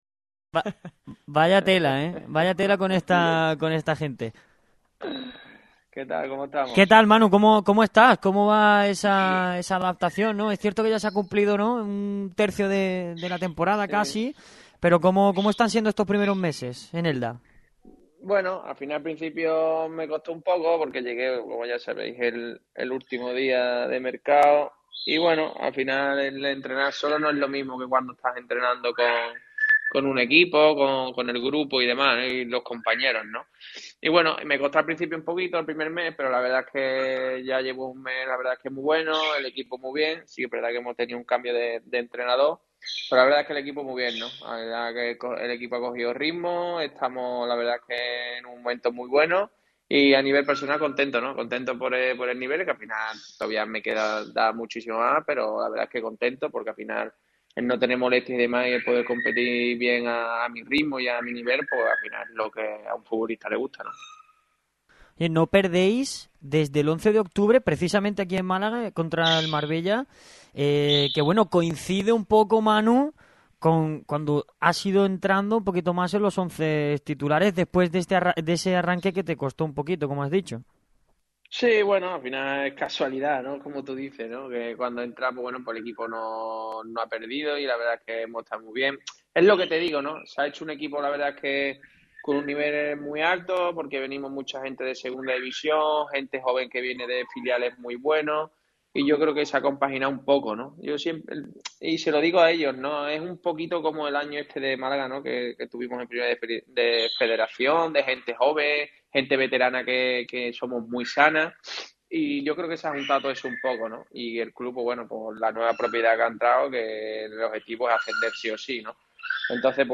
Entrevistón exclusivo en Radio MARCA Málaga.